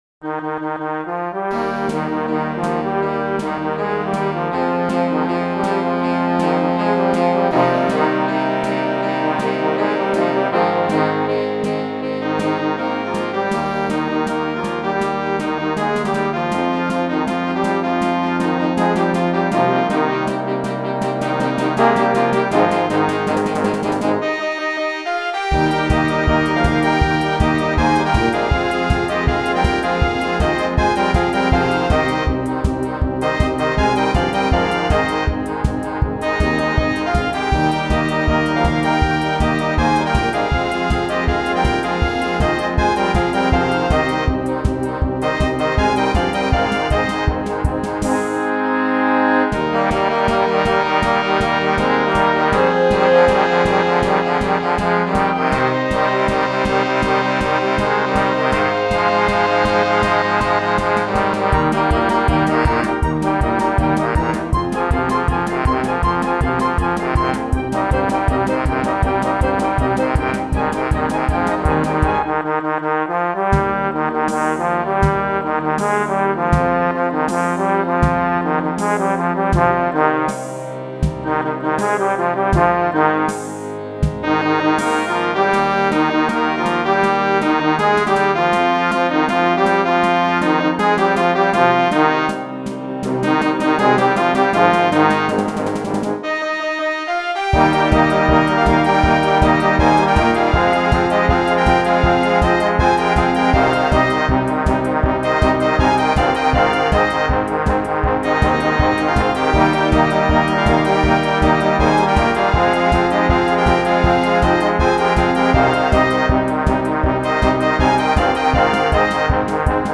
authentisch, druckvoll und (fast) wie das Original.
erstmals für Blasmusik spielbar.